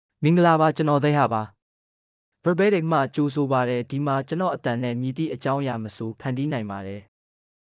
Thiha — Male Burmese AI voice
Thiha is a male AI voice for Burmese (Myanmar).
Voice sample
Listen to Thiha's male Burmese voice.
Thiha delivers clear pronunciation with authentic Myanmar Burmese intonation, making your content sound professionally produced.